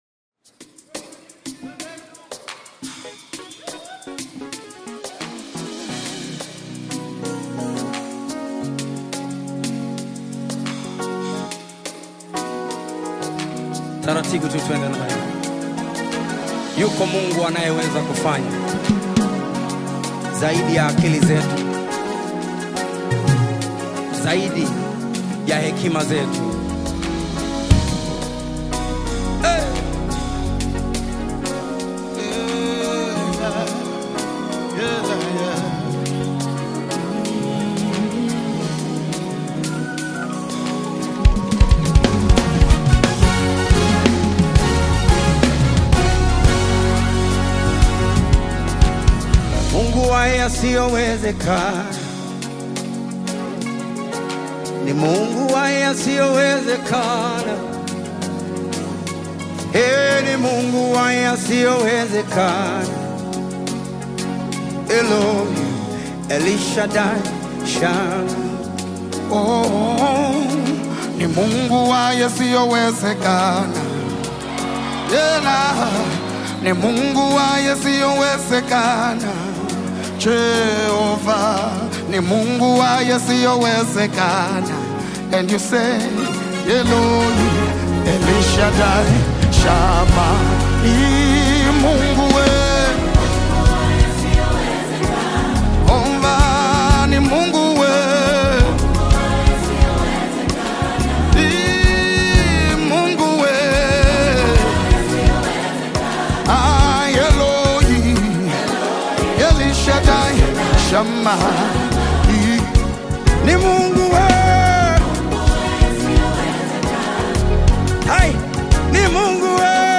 Award winning singer